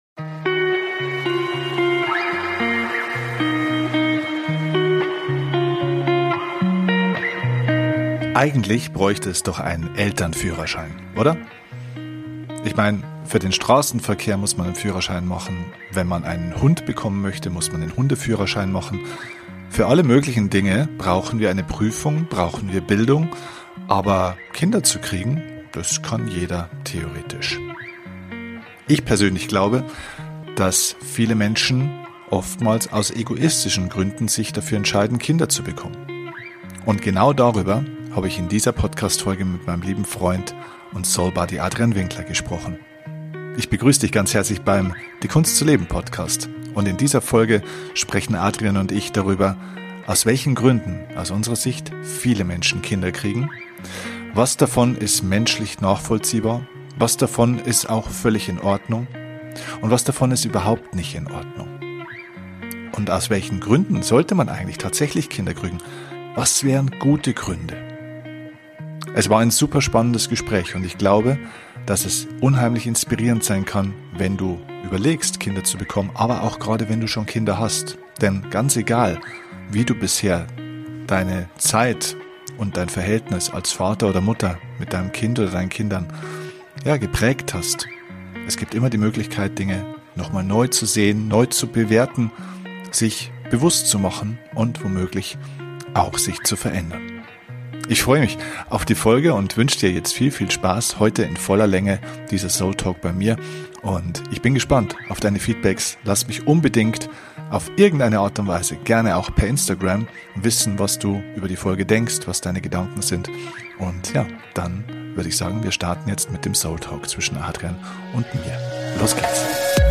Hier geht es nur um den Austausch zweier Freunde. Wie immer beim SOULTALK gibt es kein Skript!